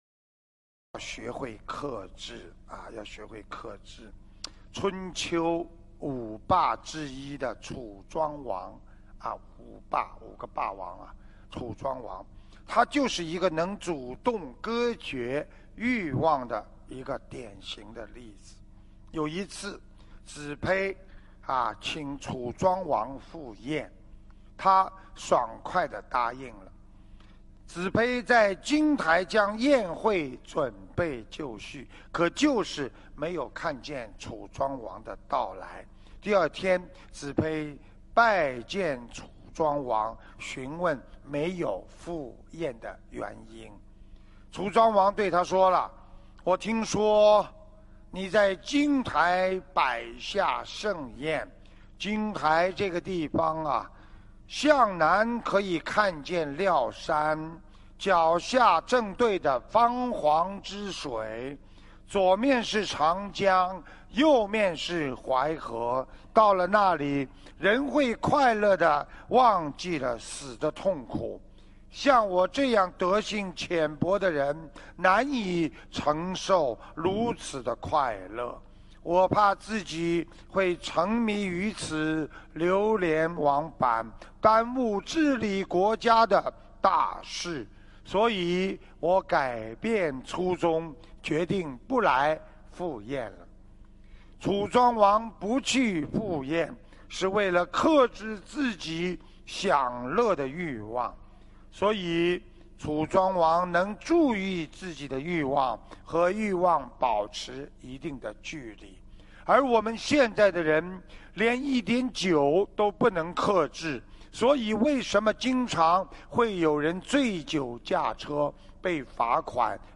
音频：要学会克制自己的欲望！2015年12月5日墨尔本开示